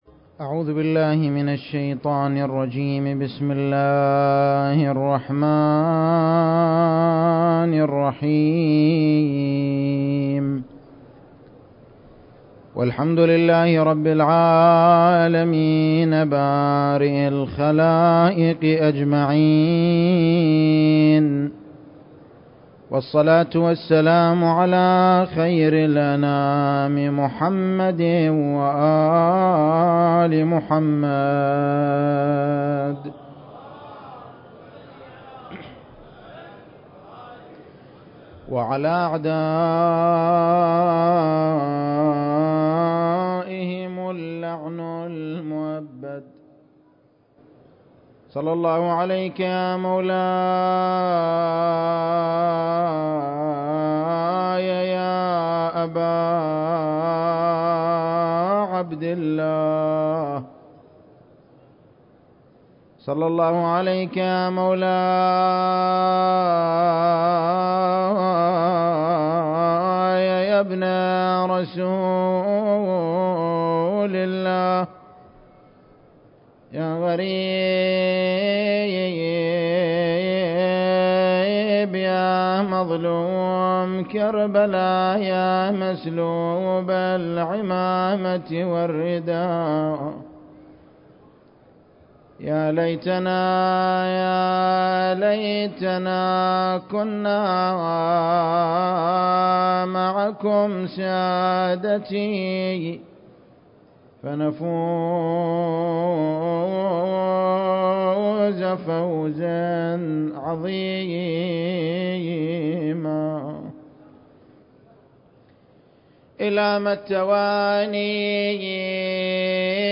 المكان: العتبة الحسينية المقدسة التاريخ: 2020